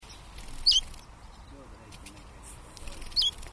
Between 11 September and 9 October I personally ringed,measured and photographed 45 Chiffchaffs at the Laajalahti ringing site, and of these, 18 called in the hand or immediately after release, all using the sweeoo call.